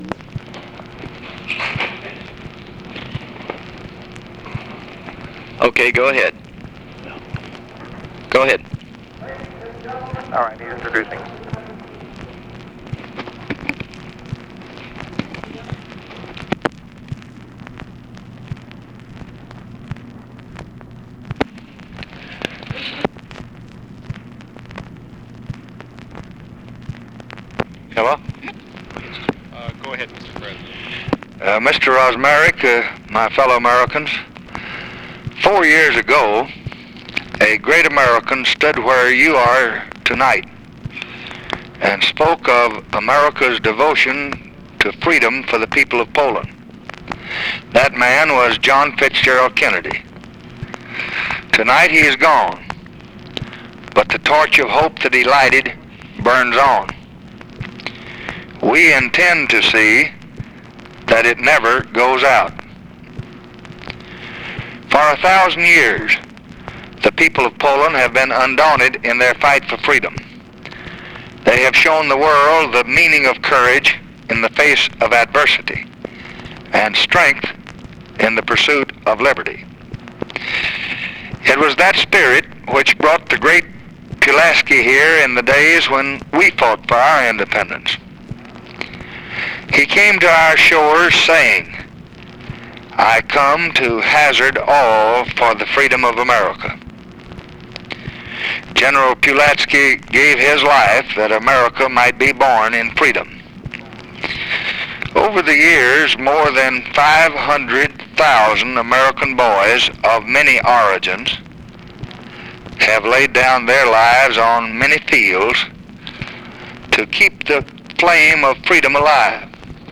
LBJ DELIVERS REMARKS TO POLISH-AMERICAN CONGRESS DINNER VIA TELEPHONE, PLEDGING US SUPPORT OF POLISH FREEDOM, DESCRIBES DIPLOMATIC CONTACTS WITH POLAND
Conversation with POLISH-AMERICAN CONGRESS DINNER and SIGNAL CORPS OPERATOR, September 19, 1964
Secret White House Tapes